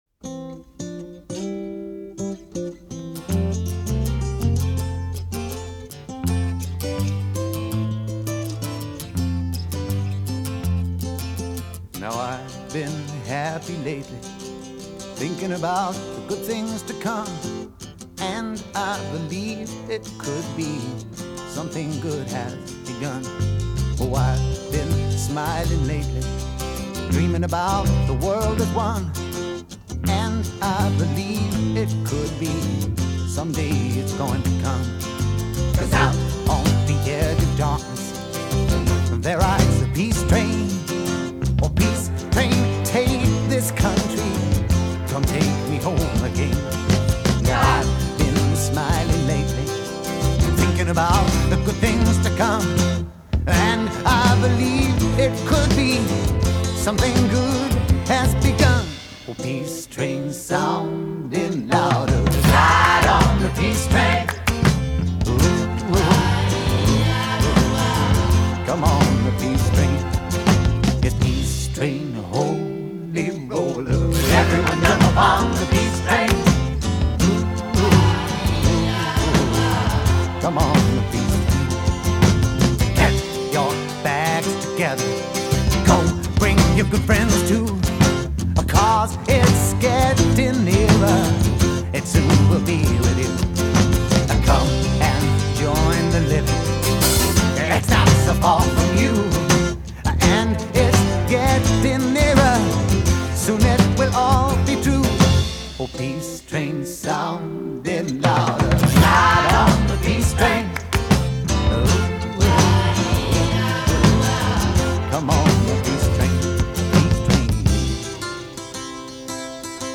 Una bella melodía